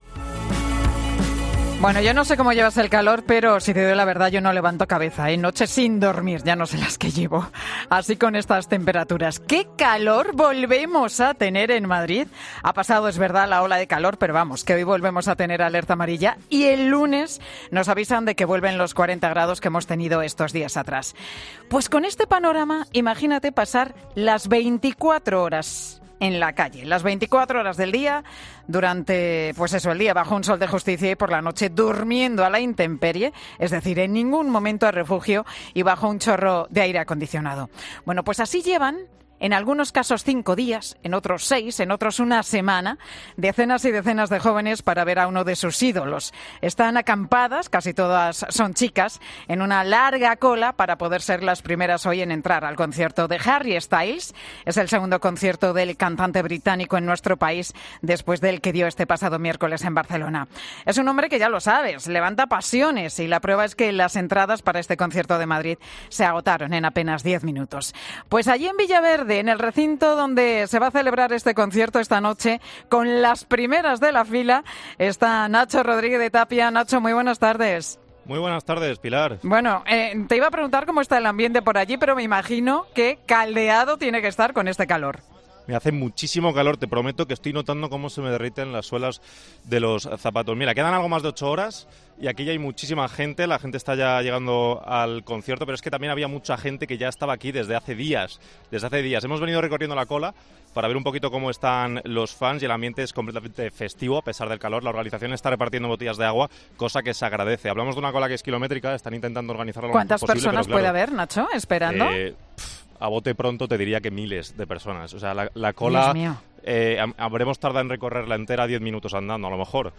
En 'Mediodía COPE' hablamos con dos chicas que asisten en la capital al concierto del cantante británico